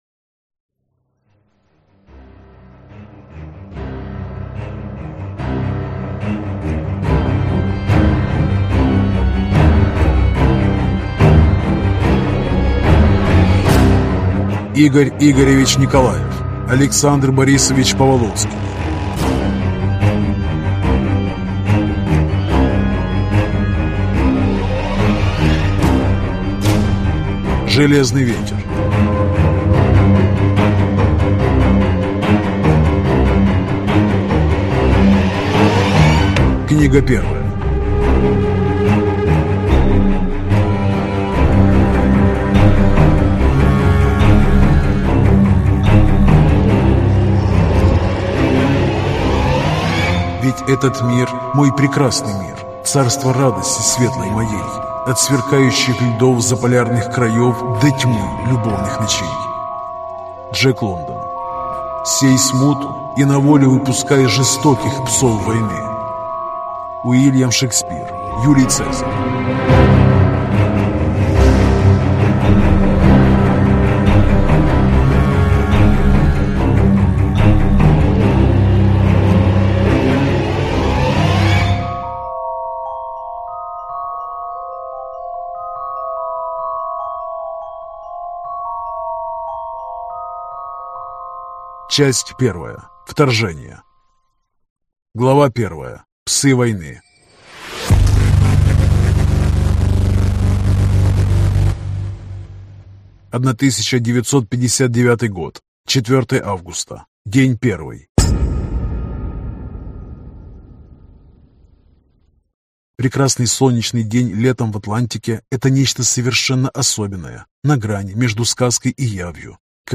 Аудиокнига Железный ветер. Путь войны. Там, где горит земля (сборник) | Библиотека аудиокниг